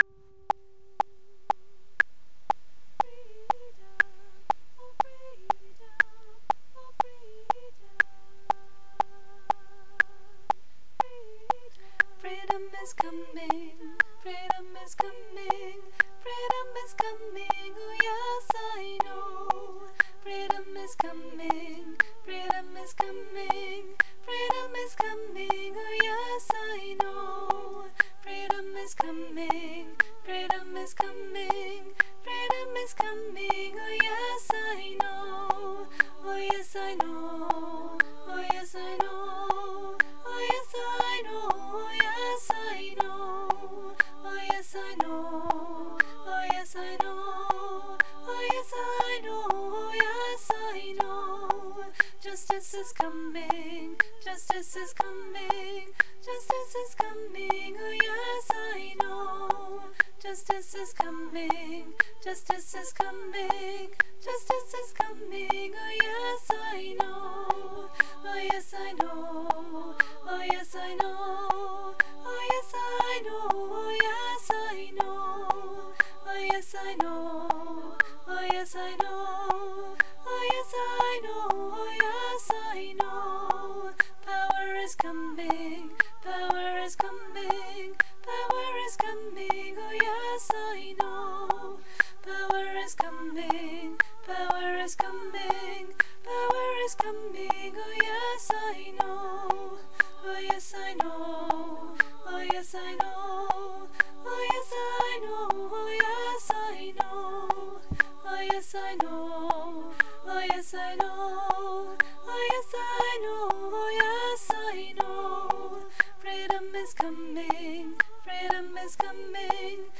Freedom is Coming for SCF19 HIGHER ALTO